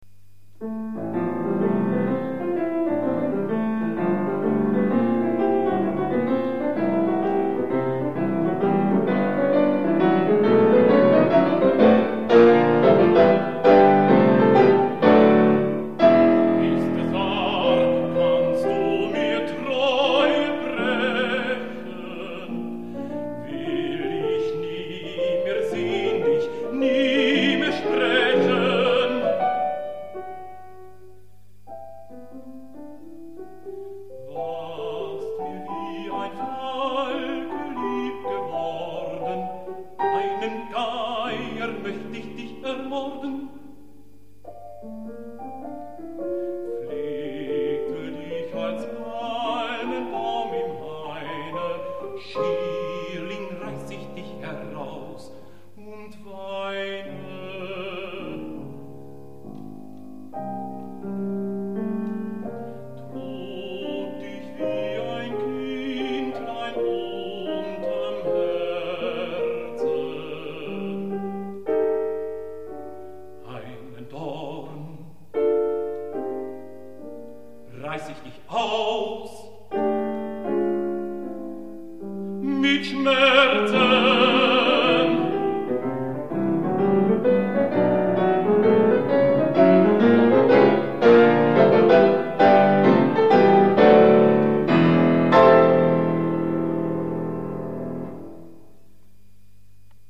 Ist es wahr, kannst du mir Treue brechen Bariton